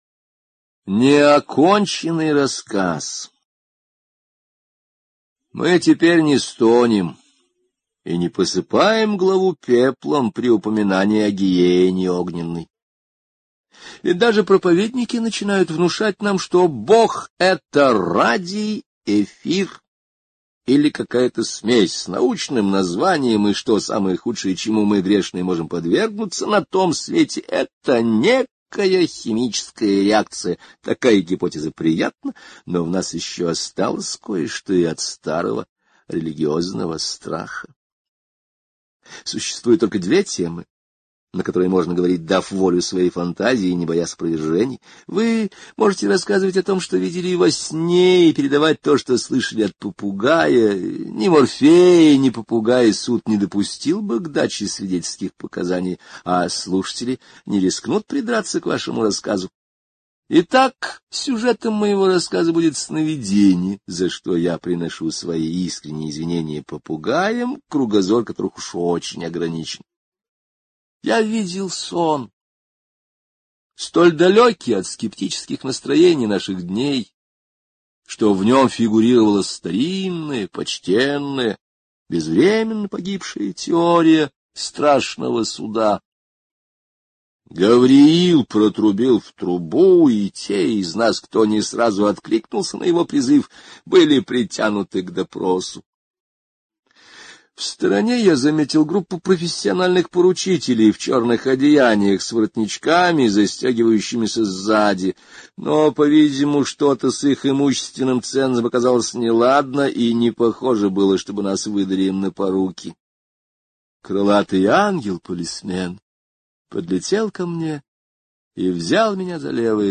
Неоконченный рассказ — слушать аудиосказку Генри О бесплатно онлайн